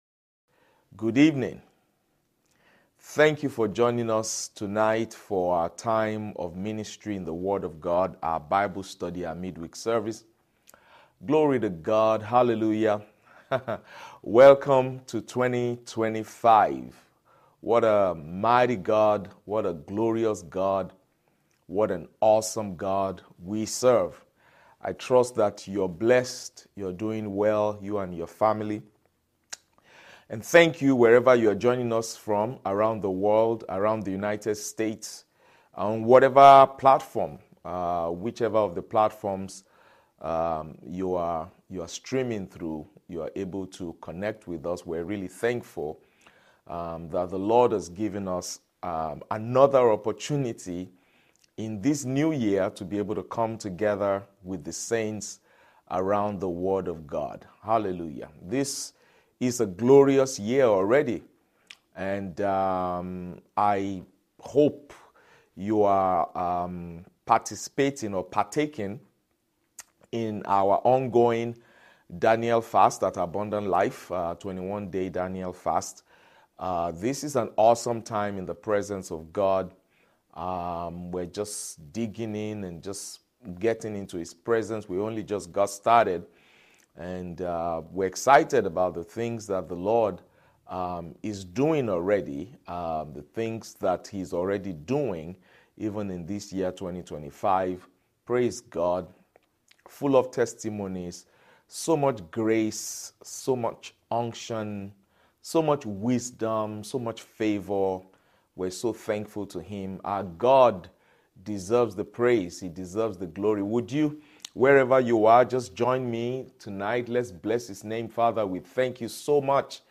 Sermons – Abundant Life International Church